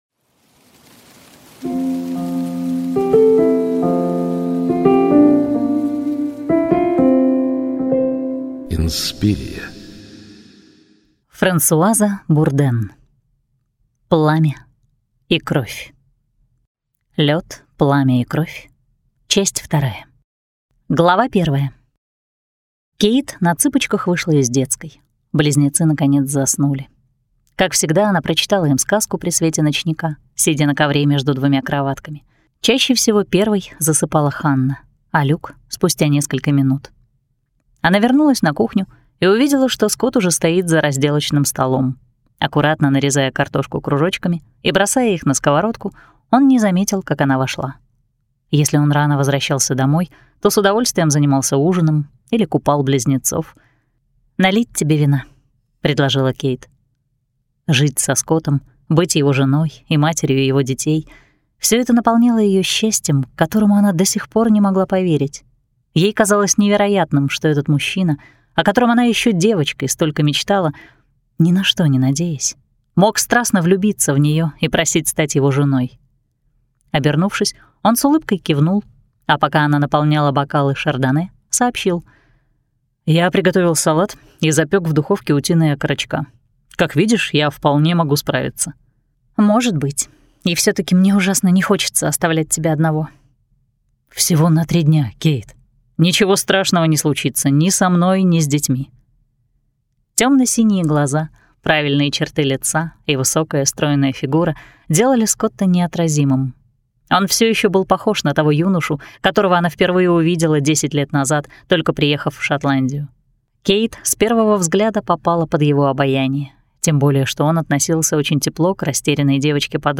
Аудиокнига Пламя и кровь | Библиотека аудиокниг